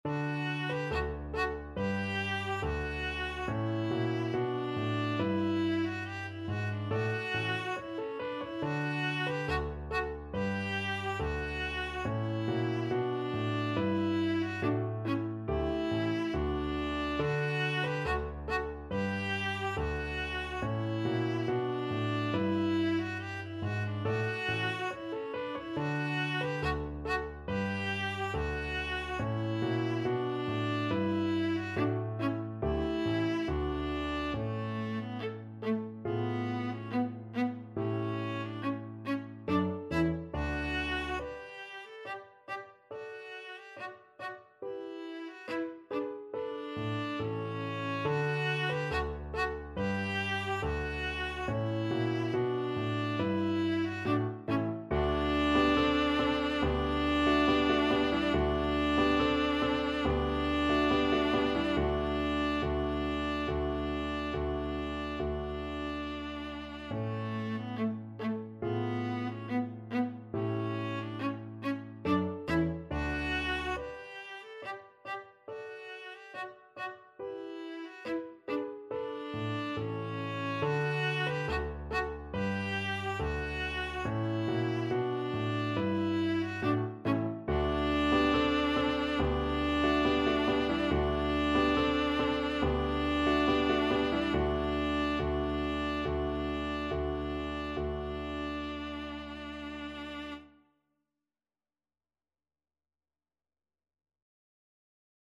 2/4 (View more 2/4 Music)
Classical (View more Classical Viola Music)